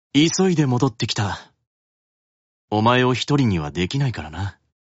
Character Voice Files